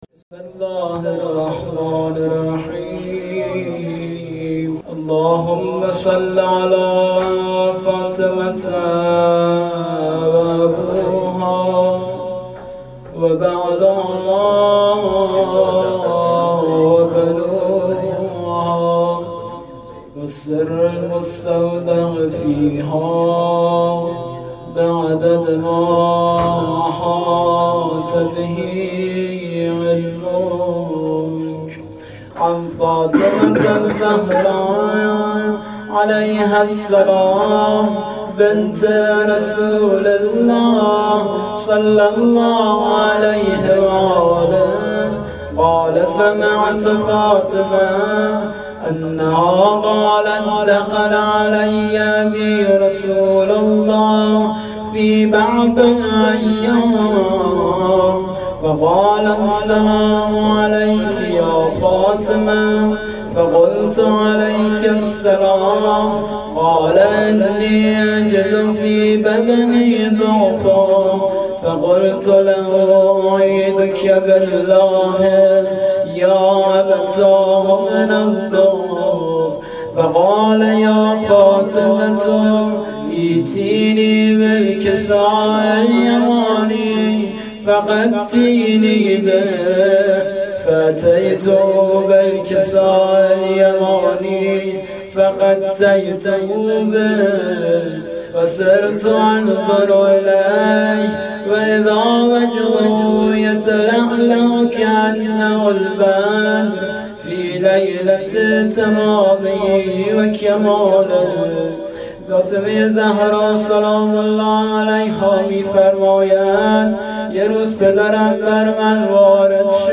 حسینیه, هیئت رایت الهدی کمالشهر
مداحی فاطمیه